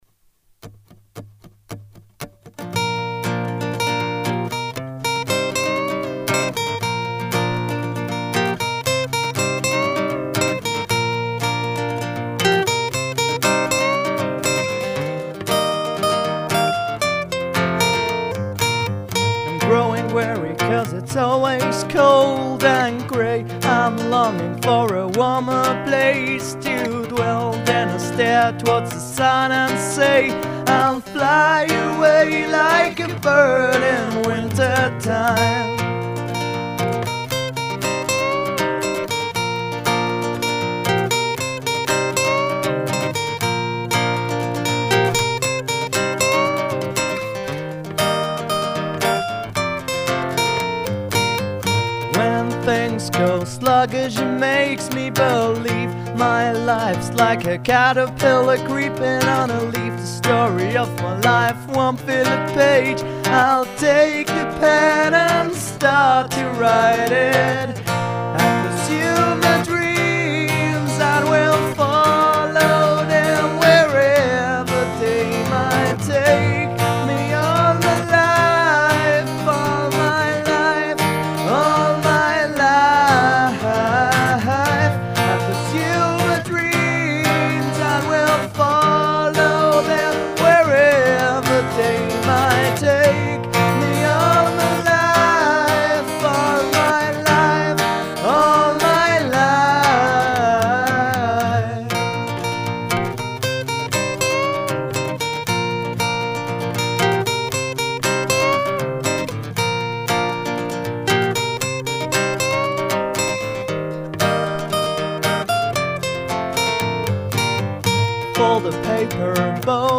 thats good. you kinda struggle to hit some of the notes while singing but besides that, its good
the singing is a bit off in the chorus on some notes, but it's a pretty high pitch...
Pretty cool lead throughout the song.
However, you're in and out of key throughout this song.
By the way, particularly nice vocal ending.